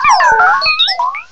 cry_not_morelull.aif